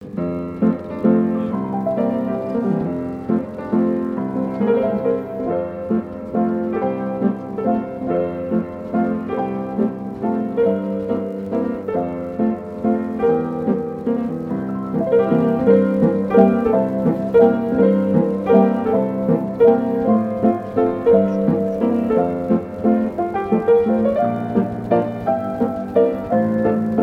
La veuve joyeuse sur piano mécanique
danse : valse
musique mécanique